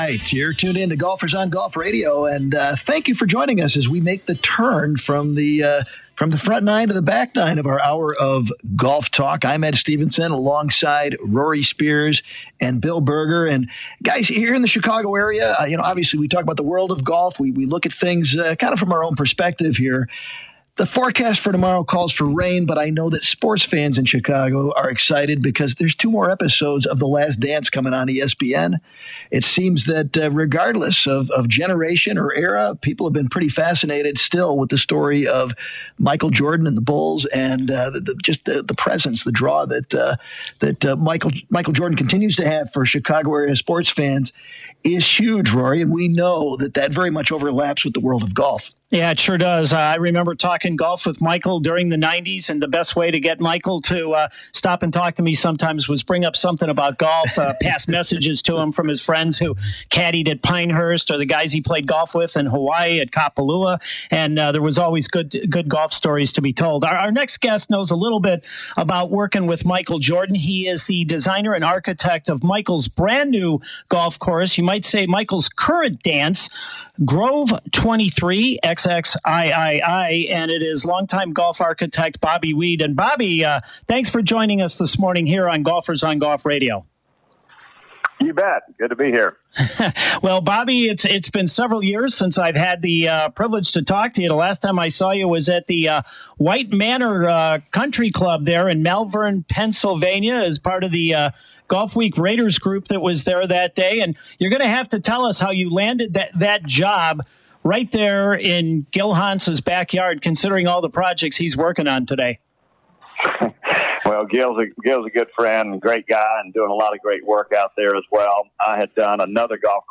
In the WCPT AM 820 Studios, home of Golfers on Golf Radio in it’s 30th season.